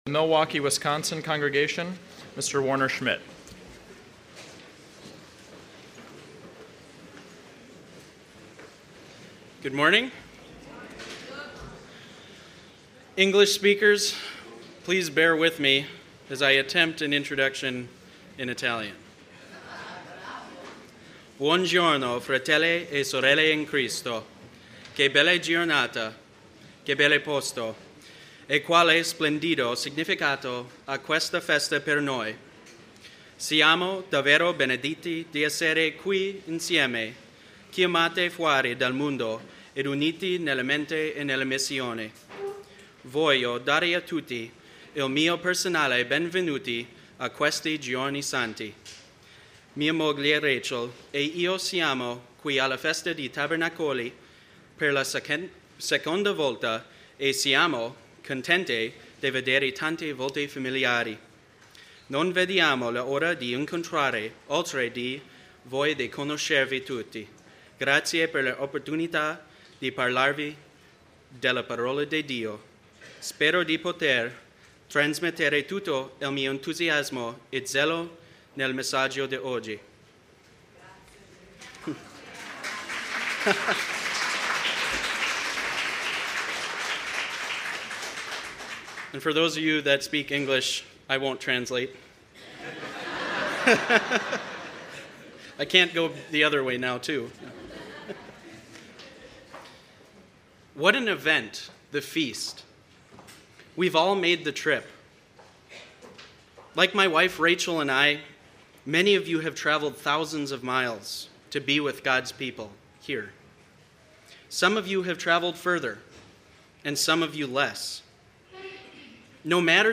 Sermonette
FOT 3rd day – Italy 2017